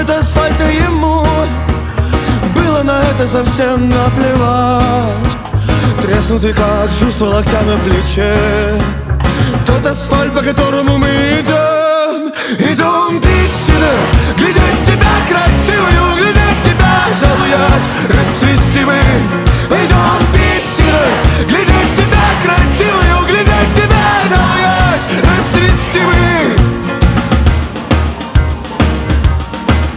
Жанр: Рок / Альтернатива / Фолк / Русские
Folk-Rock